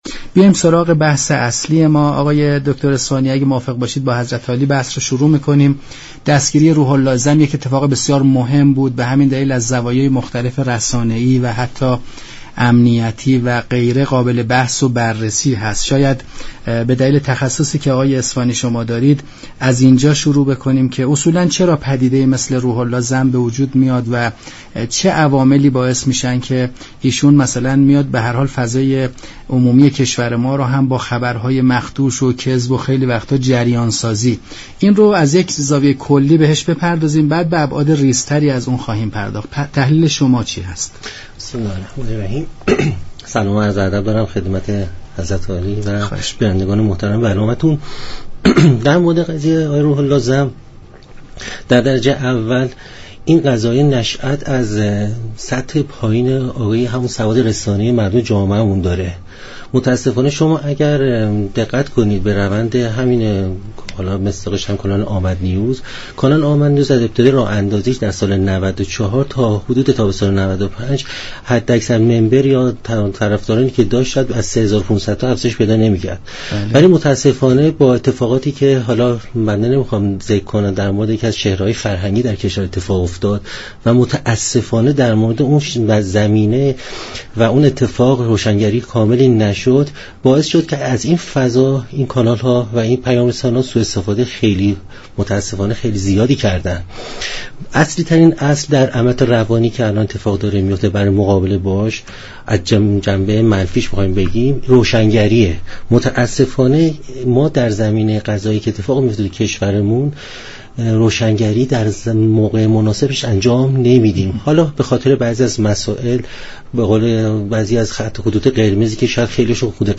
كارشناسان در برنامه بحث روز گفتند : آمدنیوز با استفاده از اخبار جعلی، مخاطب را در خیل عظیمی از اخبار دچار سردرگمی می كرد